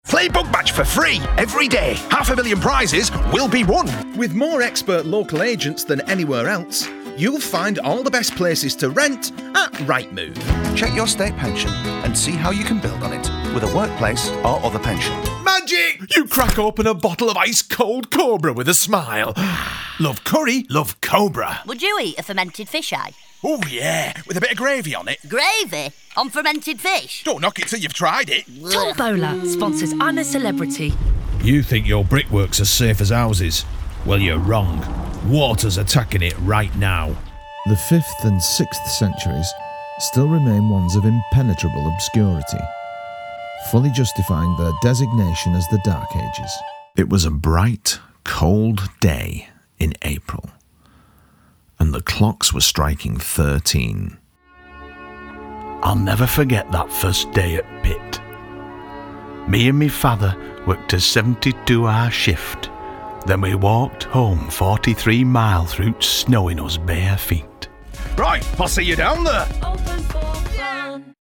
PLAY VOICE REEL
Production • Composition • Professional Voiceover